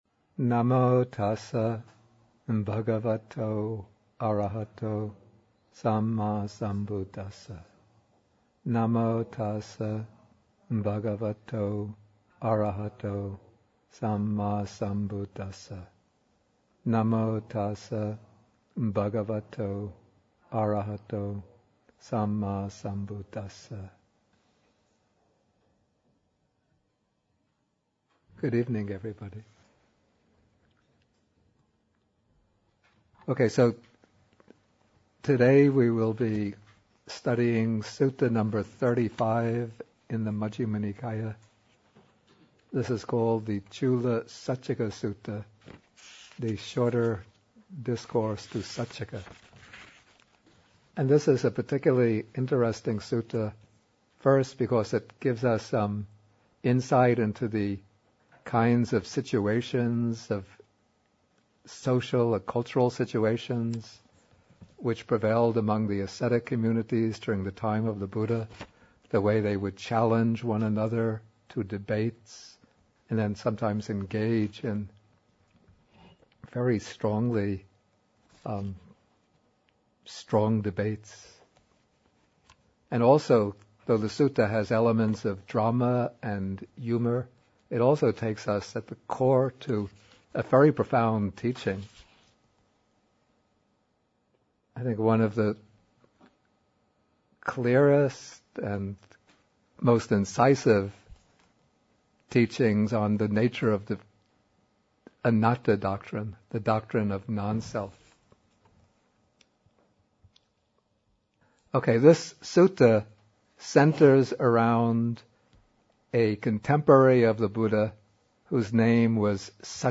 MN35, Bhikkhu Bodhi at Bodhi Monastery (lectures 131 and 132)